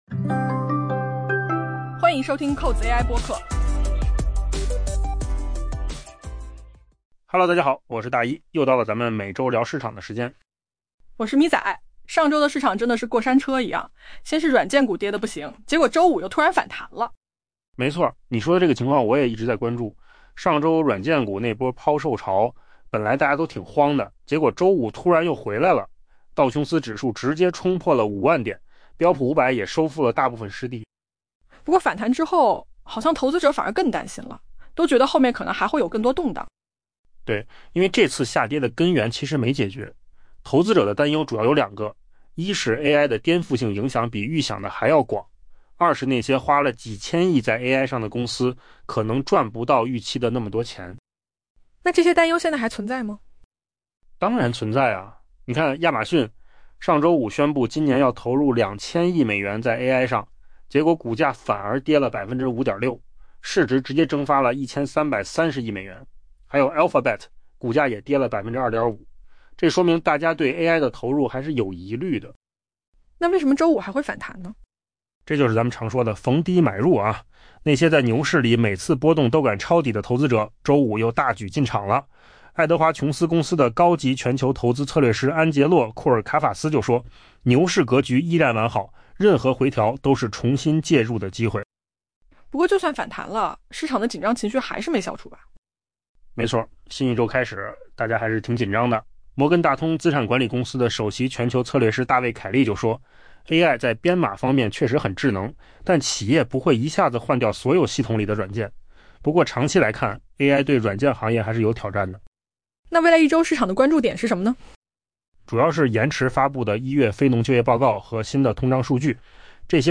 AI 播客：换个方式听新闻 下载 mp3 音频由扣子空间生成 上周席卷大型科技股、私募信贷甚至公司债券市场的软件股抛售潮，最终以一场显著反弹告终，但这反而让投资者为未来更多的动荡做好了准备。